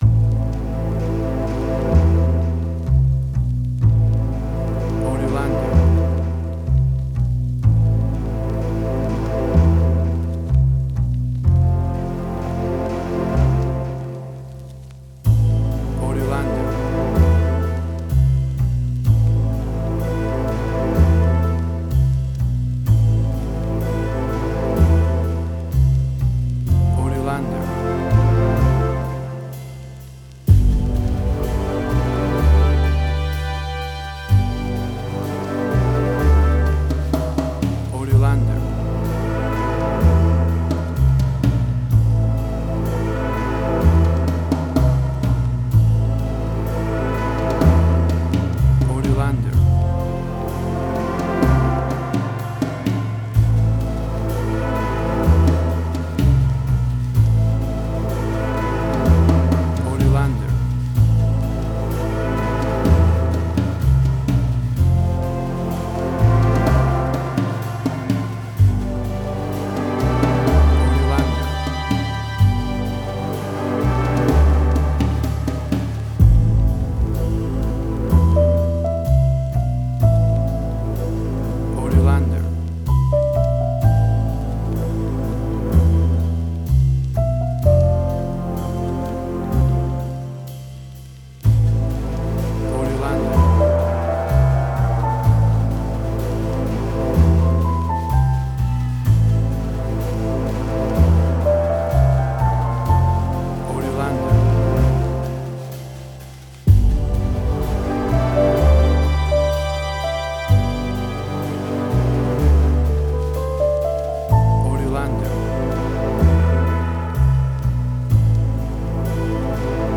Modern Film Noir.
Tempo (BPM): 63